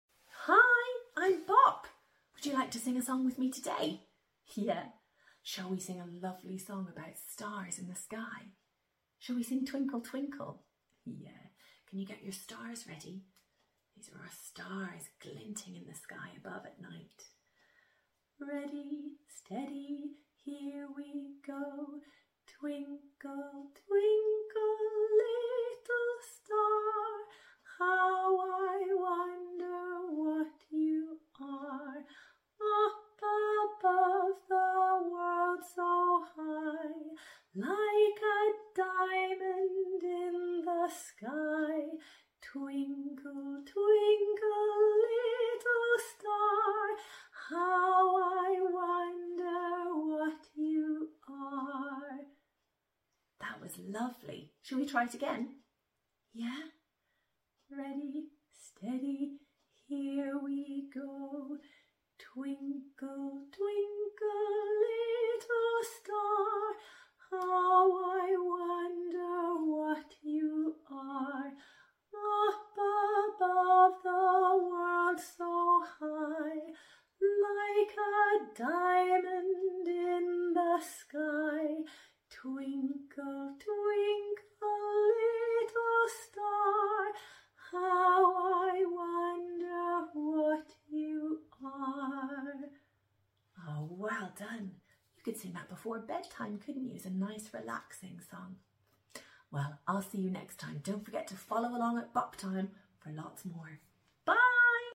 Come and sing Twinkle, twinkle little star with me! A classic children's song, join in with the lovely hand actions to match the song. This is a great song for winding down at the end of a day or for a bedtime lullaby to sing with your child.